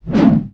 Index of /90_sSampleCDs/E-MU Producer Series Vol. 3 – Hollywood Sound Effects/Science Fiction/Columns
BIG WHOOSH.wav